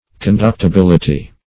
Search Result for " conductibility" : The Collaborative International Dictionary of English v.0.48: Conductibility \Con*duct`i*bil"i*ty\ (k[o^]n*d[u^]k`t[i^]*b[i^]l"[i^]*t[y^]), n. [Cf. F. conductibilit['e].] 1.